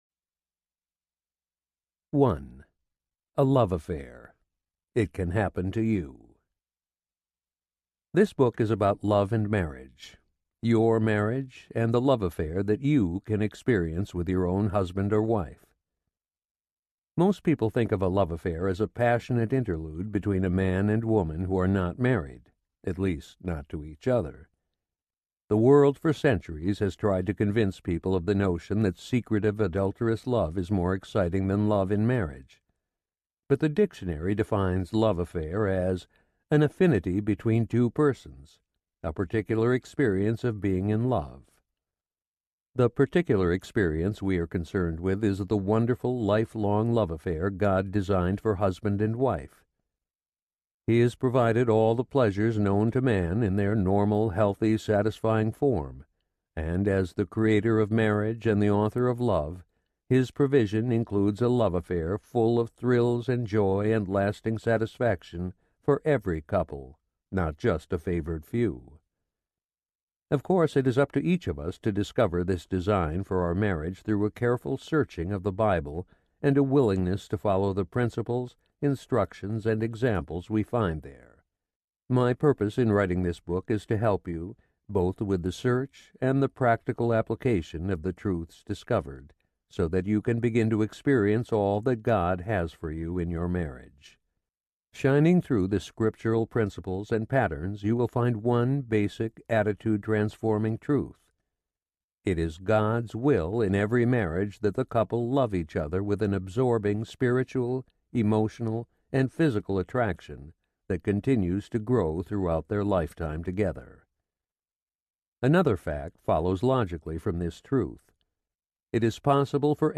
Love Life for Every Married Couple Audiobook
9.2 Hrs. – Unabridged